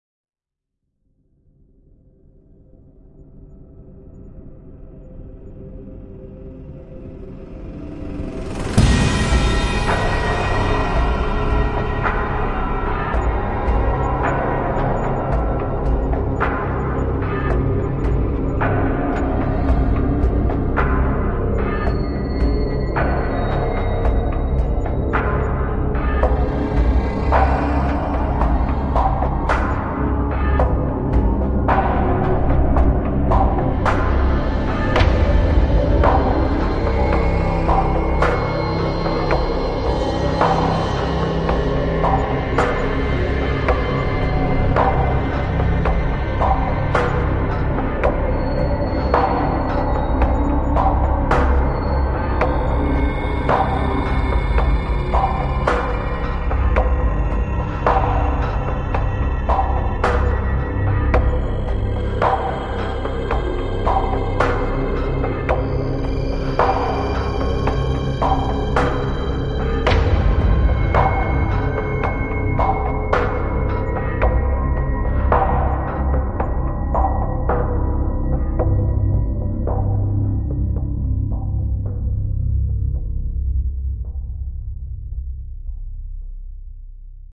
Download Horror Movie sound effect for free.
Horror Movie